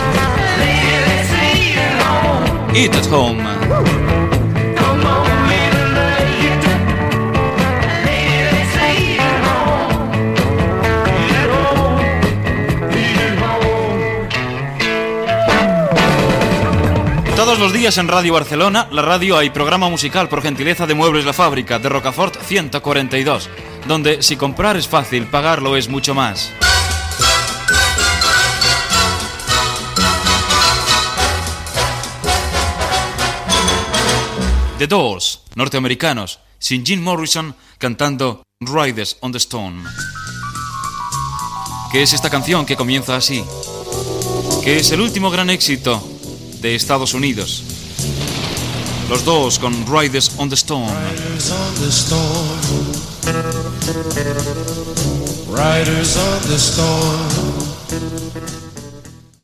Tema musical, publicitat i presentació d'una novetat musical.